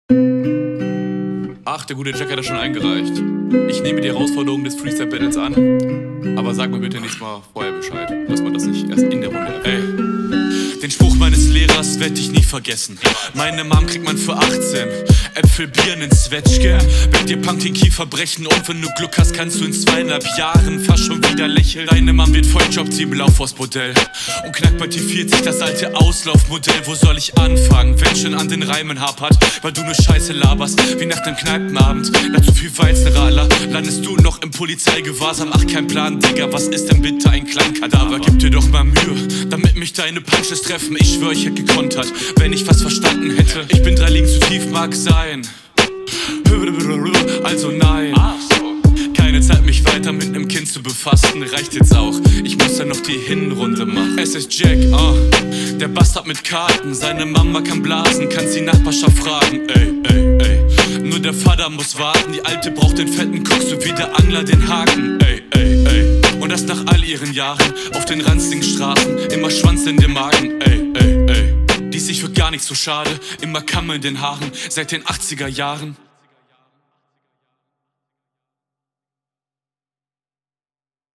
Qualität und Delivery machen das Ganze halt einfach besser